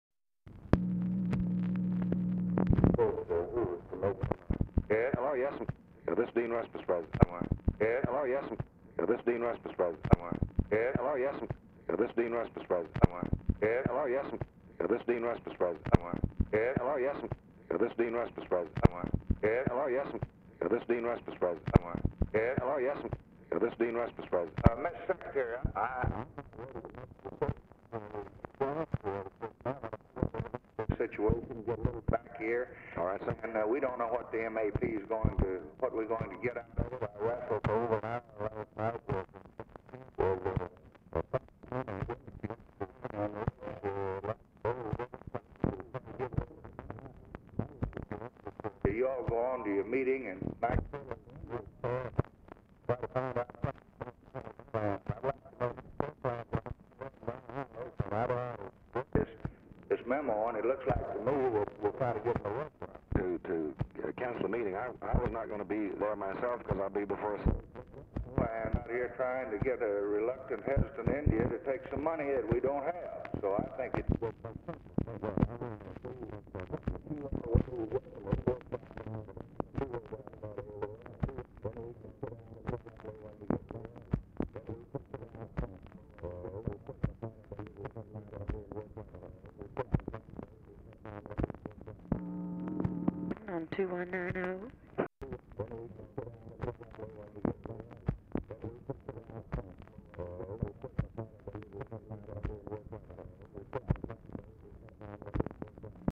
VERY POOR SOUND QUALITY BECAUSE DICTABELT IS SPLITTING; RECORDING ENDS BEFORE CONVERSATION IS OVER
Format Dictation belt
Specific Item Type Telephone conversation Subject Congressional Relations Defense Diplomacy Foreign Aid Legislation South Asia